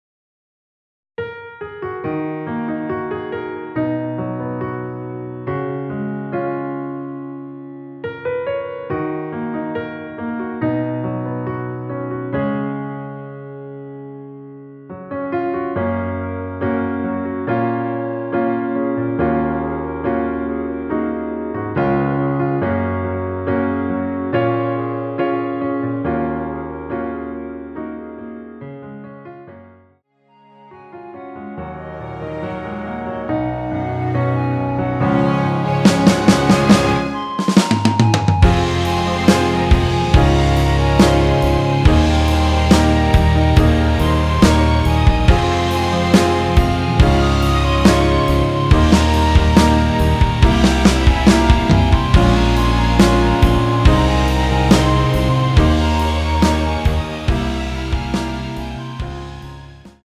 원키에서(-2)내린 멜로디 포함된 MR입니다.
F#
앞부분30초, 뒷부분30초씩 편집해서 올려 드리고 있습니다.